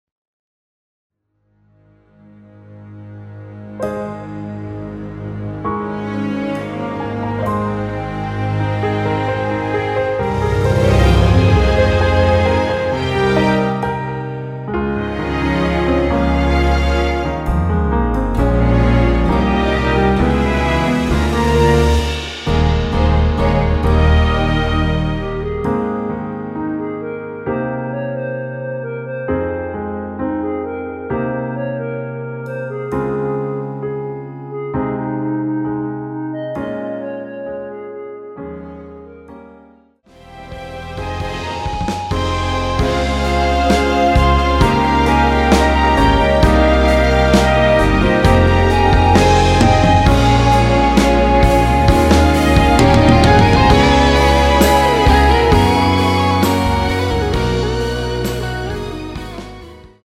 원키에서(-1)내린 멜로디 포함된 MR입니다.
Ab
앞부분30초, 뒷부분30초씩 편집해서 올려 드리고 있습니다.
(멜로디 MR)은 가이드 멜로디가 포함된 MR 입니다.